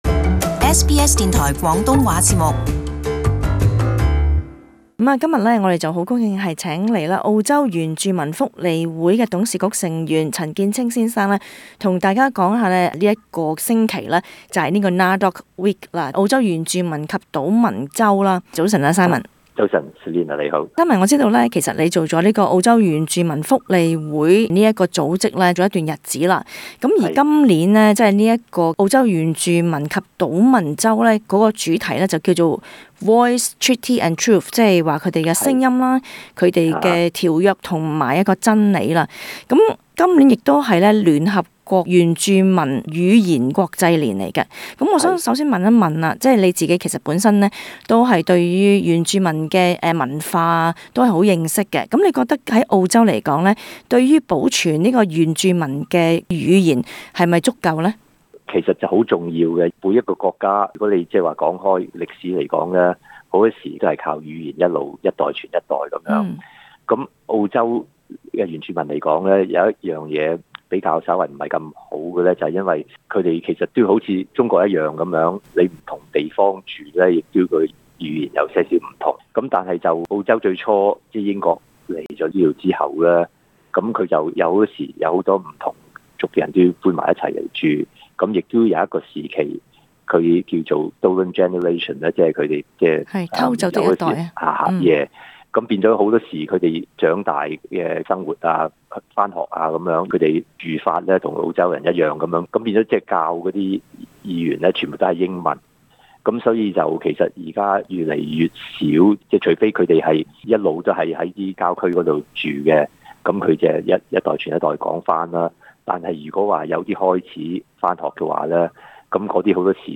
【社區專訪】澳洲的原住民及島民得到應有的權利和待遇嗎？